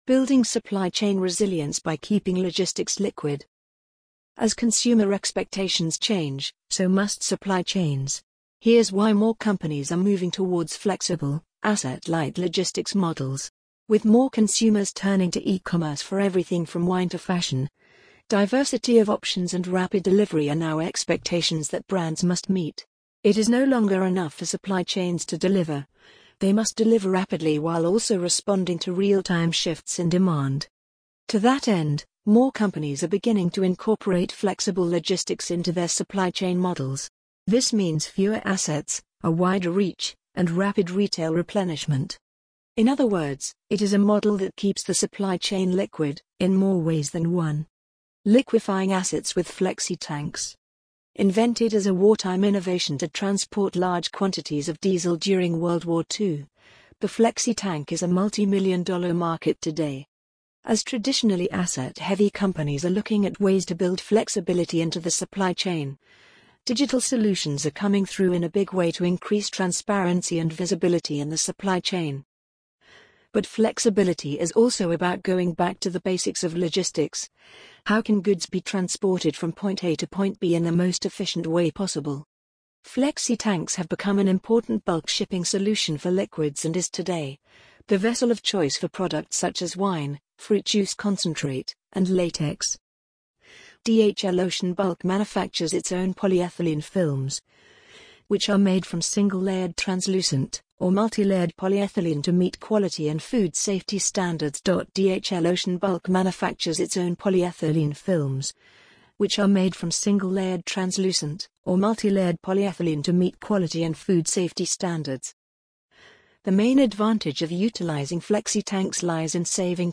amazon_polly_46877.mp3